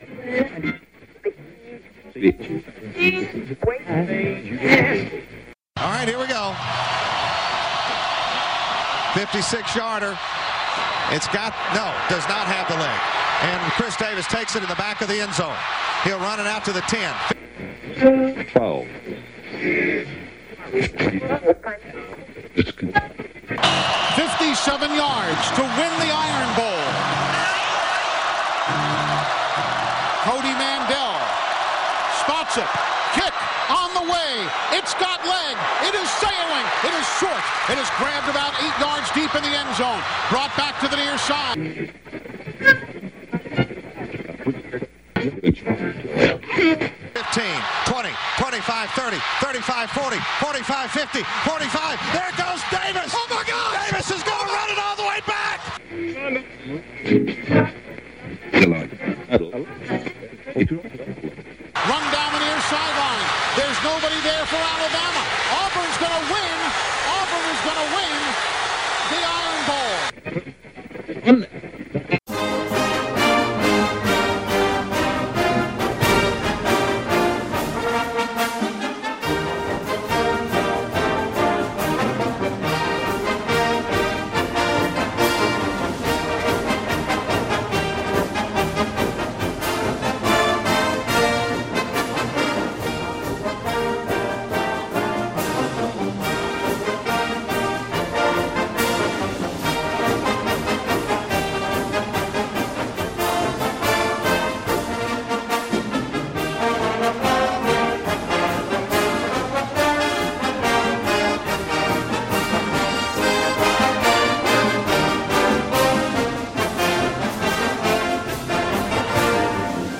Radio Theatre: What It Was Was Football (Audio)